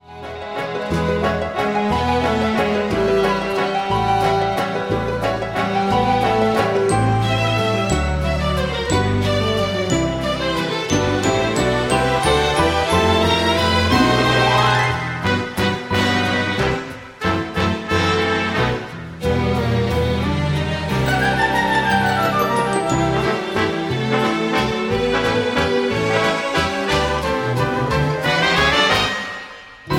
MPEG 1 Layer 3 (Stereo)
Backing track Karaoke
Pop, Oldies, Musical/Film/TV, 1960s